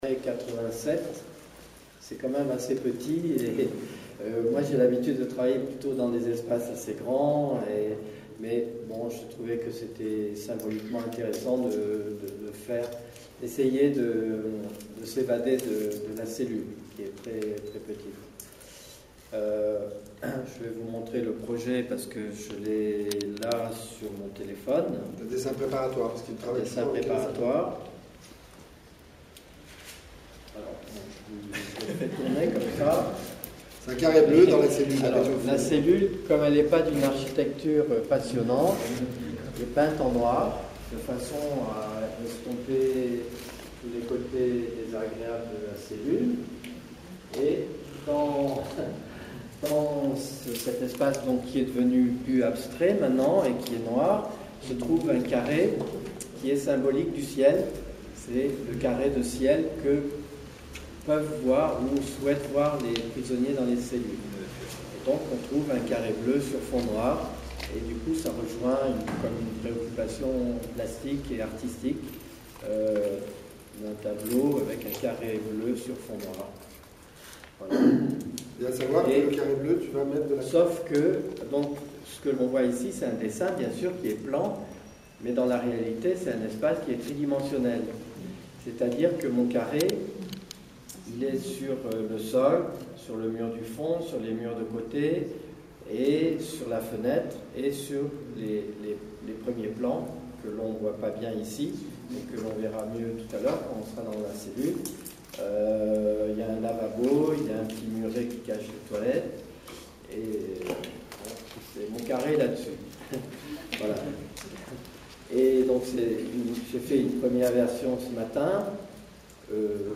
C’est là, dans ce hall, dont les murs « pèlent » littéralement leur peinture épaisse, que la rencontre avec Georges Rousse se fait.